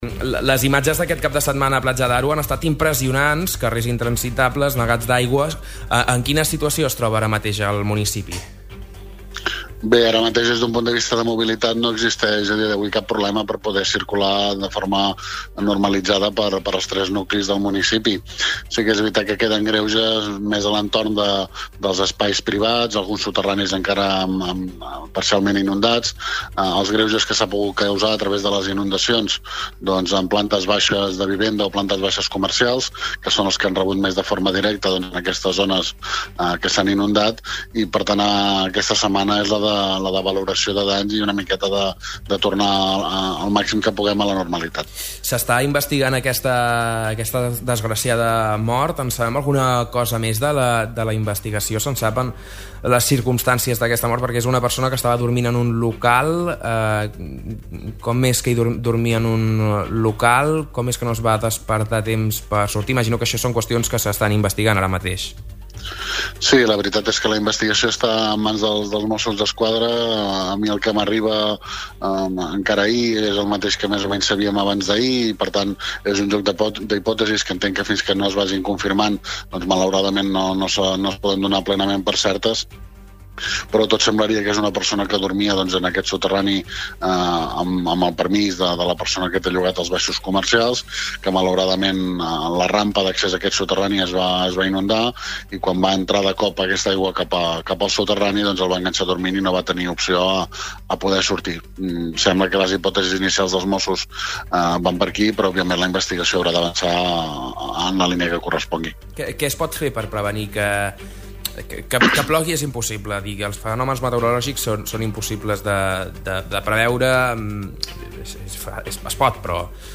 L'alclade, Maurici Jiménez, avança a Ràdio Capital que la primera quinzena d'octubre comencen les obres per refer el clavegueram de l'avinguda Doctor Flèming.